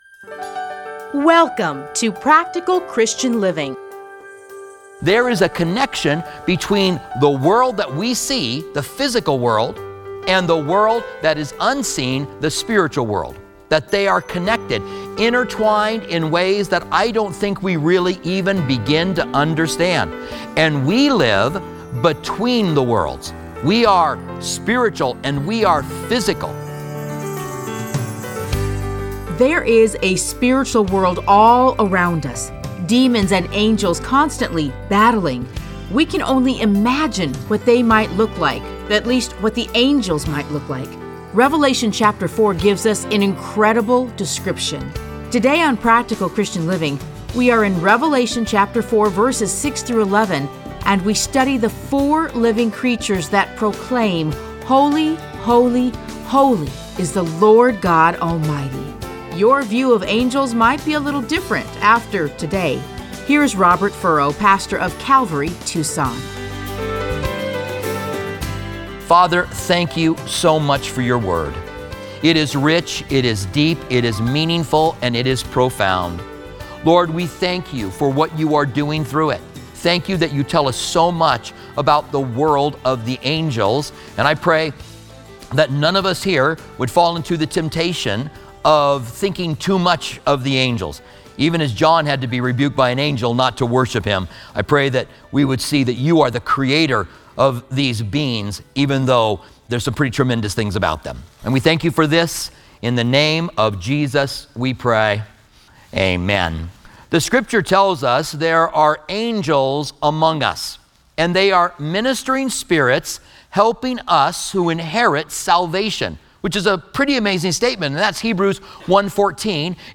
Listen to a teaching from Revelation 4:4-6.